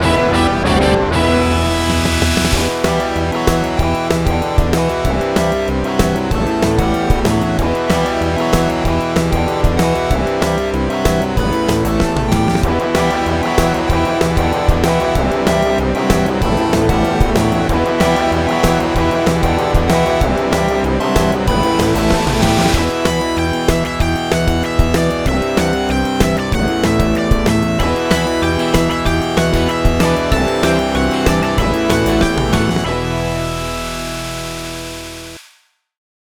Game Music
I composed using music soundfonts.